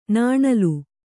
♪ nāṇalu